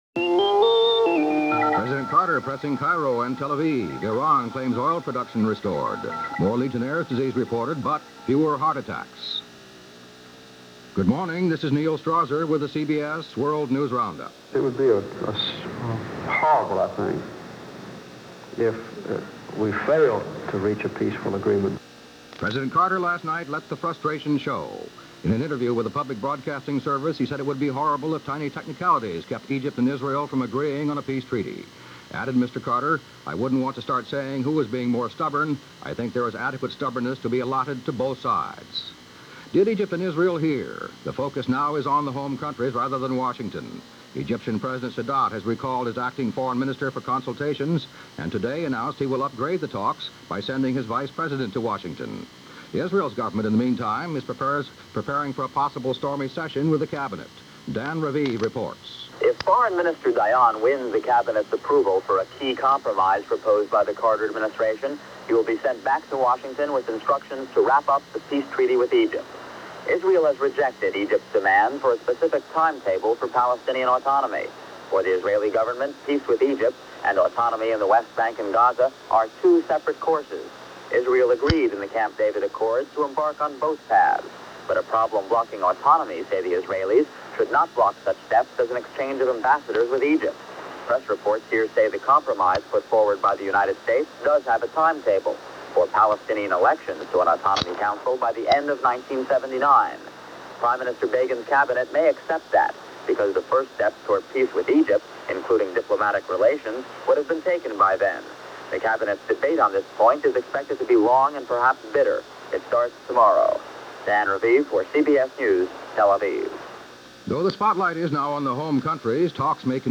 – CBS World News Roundup – November 14, 1978 – Gordon SKene Sound Collection –